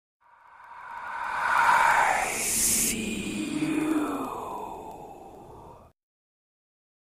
Download Horror voice sound effect for free.
Horror Voice